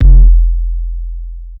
Waka KICK Edited (38).wav